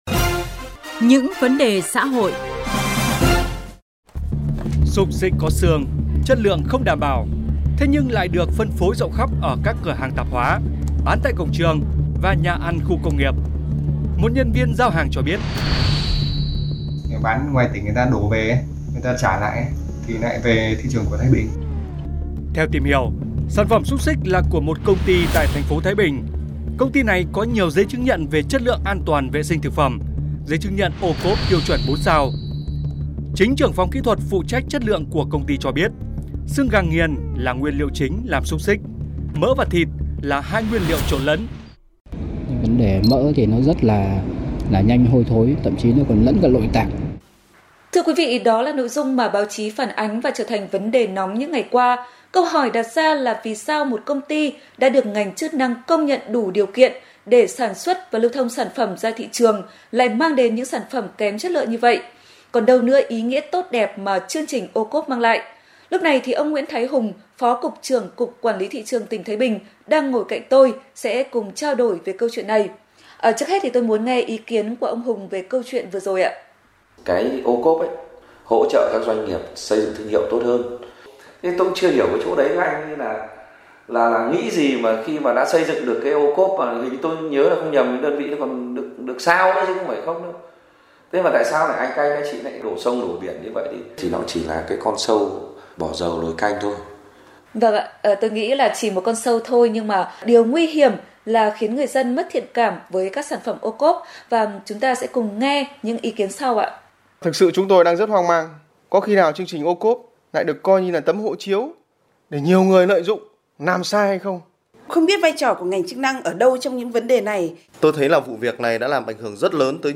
58815_THAIBINH_PHONGVAN_DUNG _DE_ OCOP_ MANG _TIENG_ OAN.mp3